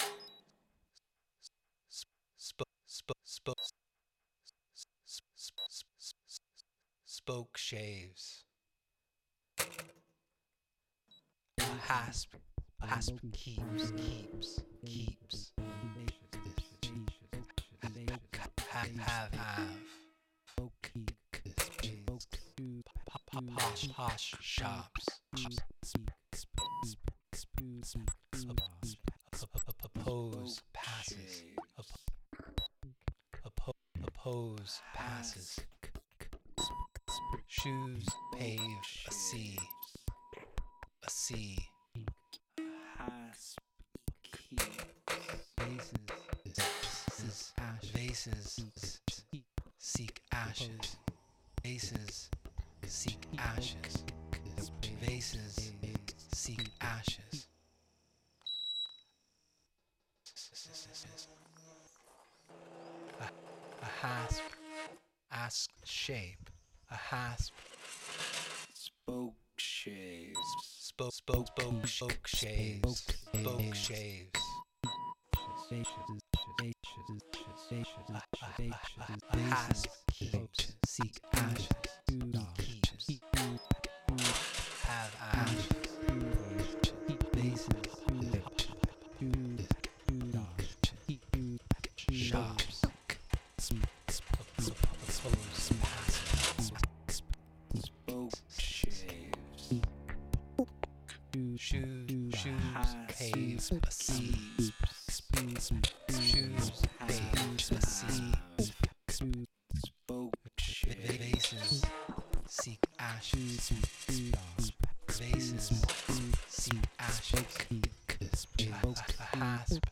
the entry gate to the castle